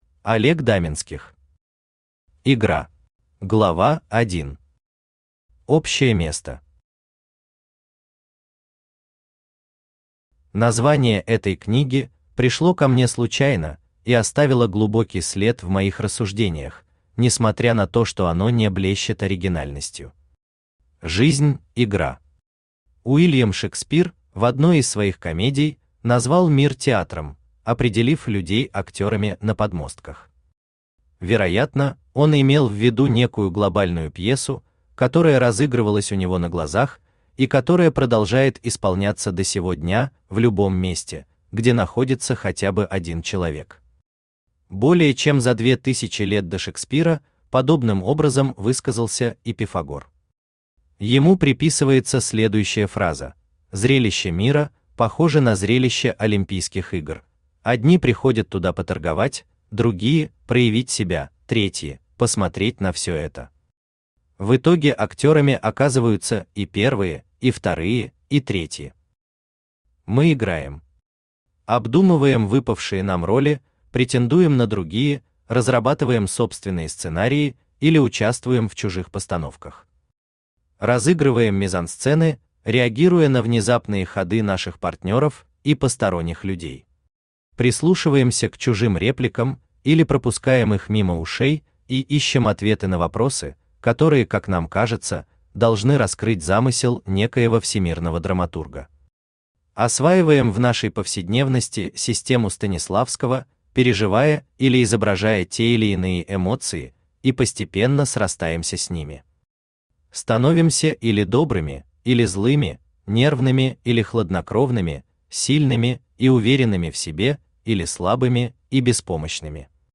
Аудиокнига Игра | Библиотека аудиокниг
Aудиокнига Игра Автор Олег Даменских Читает аудиокнигу Авточтец ЛитРес.